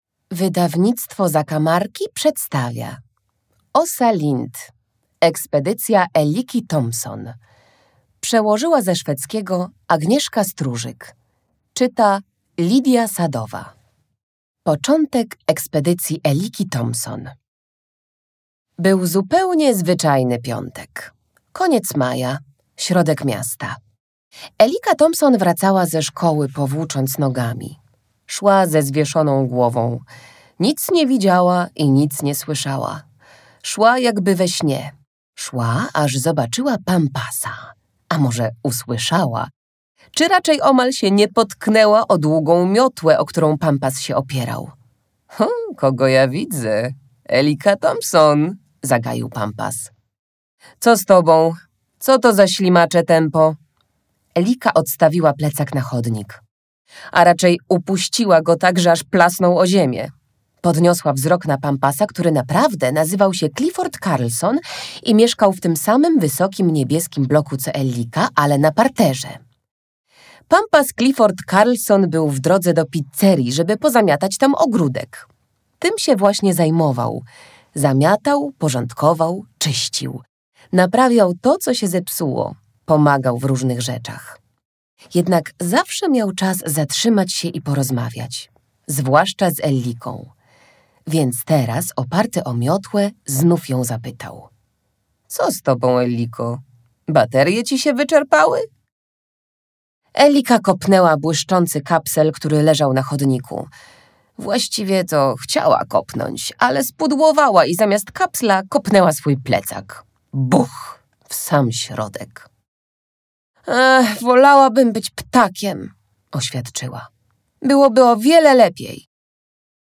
Audiobook + książka Ekspedycja Elliki Tomson, Åsa Lind.